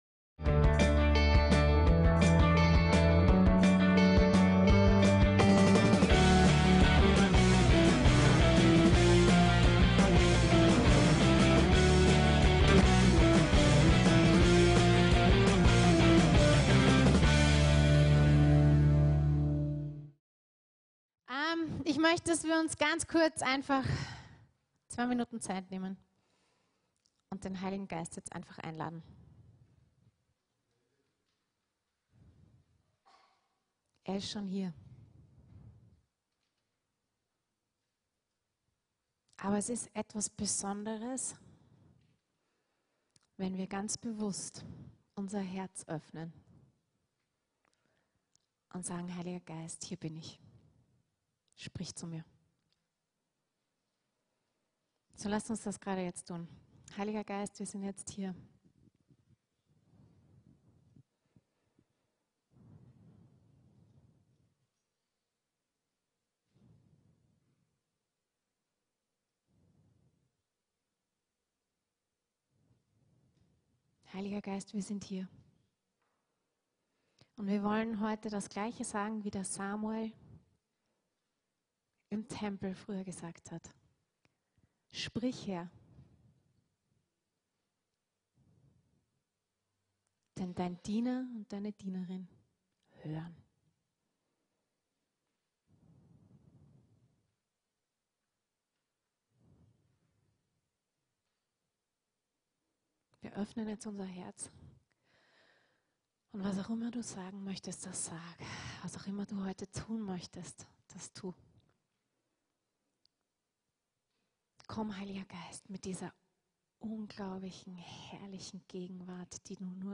FÜR DIE RETTUNG DER WELT - ZÜNDE EIN FEUER AN ~ VCC JesusZentrum Gottesdienste (audio) Podcast